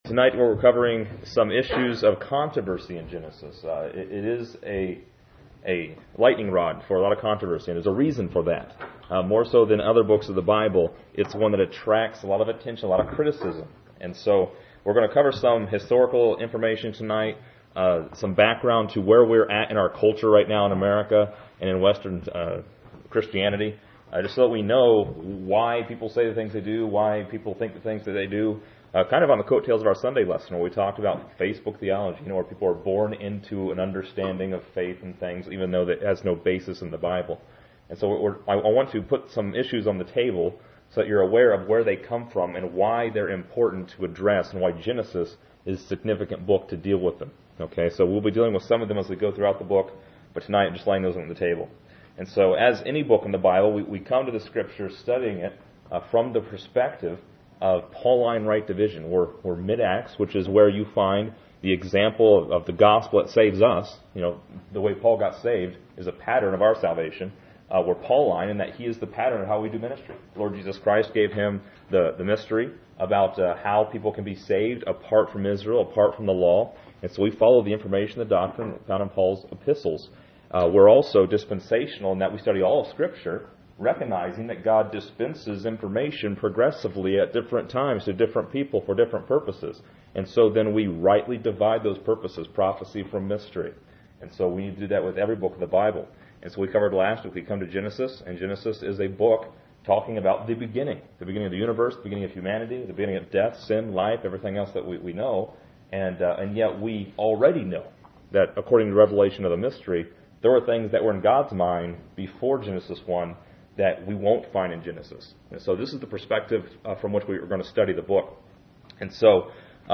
This lesson is part 02 in a verse by verse study through Genesis titled: Criticism and Controversy.